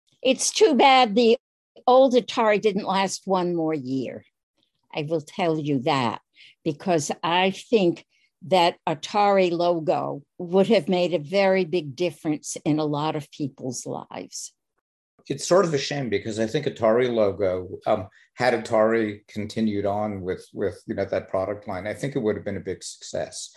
TOPIC: Logo for the Atari 8-bit. Included in this post, I also have some sound bites from the interview! https